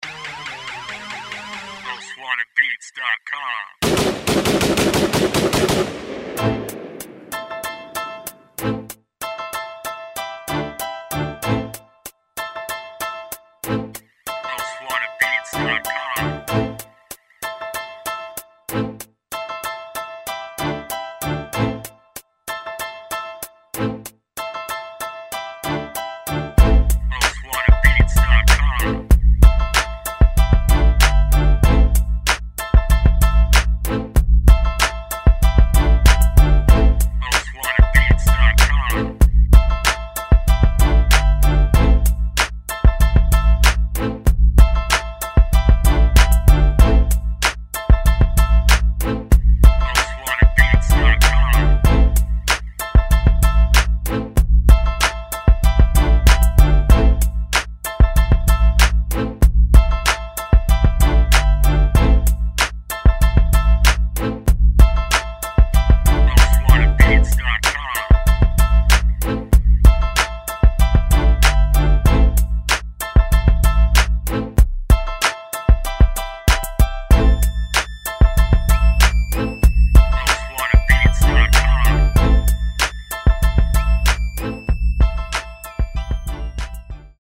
HIP HOP INSTRUMENTAL